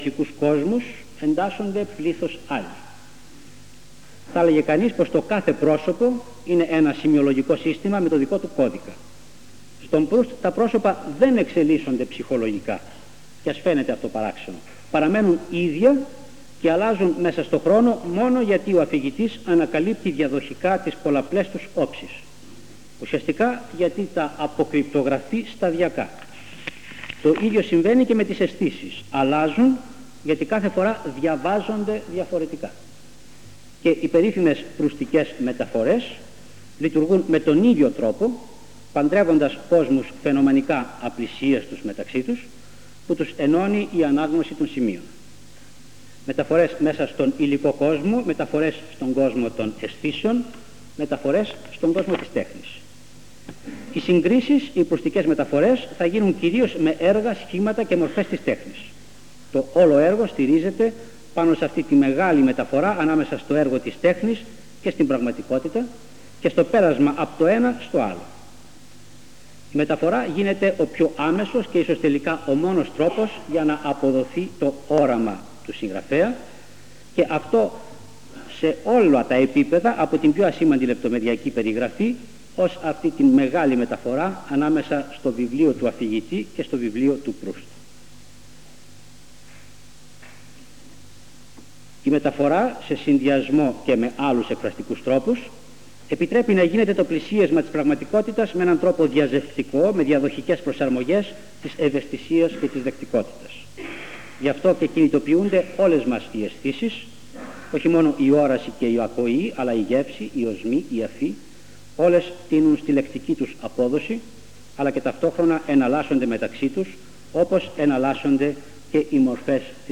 Εξειδίκευση τύπου : Εκδήλωση
Εμφανίζεται στις Ομάδες Τεκμηρίων:Εκδηλώσεις λόγου